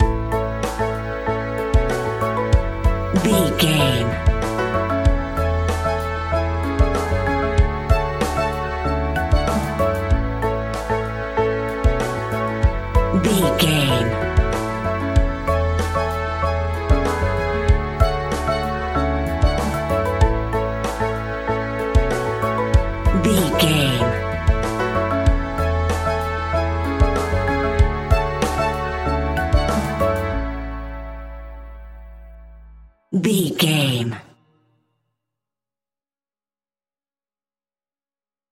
Ionian/Major
pop rock
indie pop
fun
energetic
uplifting
cheesy
instrumentals
upbeat
rocking
groovy
guitars
bass
drums
piano
organ